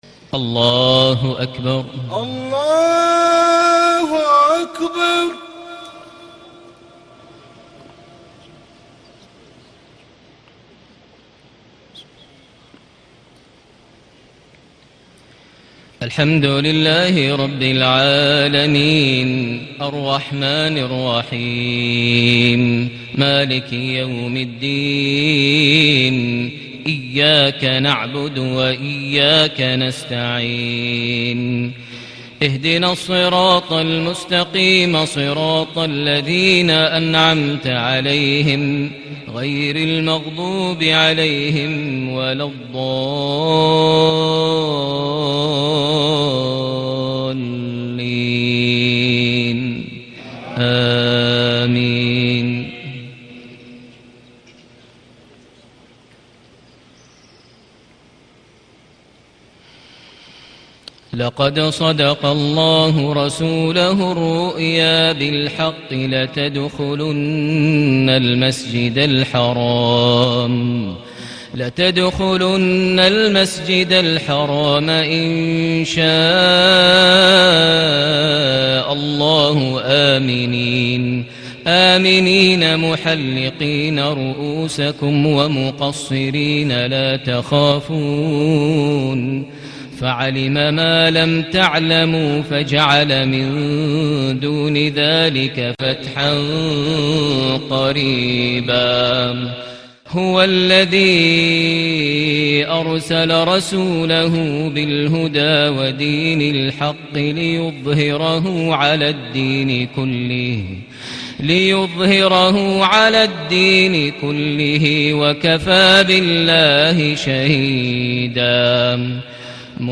صلاة المغرب ٢ربيع الأول ١٤٣٨هـ خواتيم الفتح / سورة الضحى > 1438 هـ > الفروض - تلاوات ماهر المعيقلي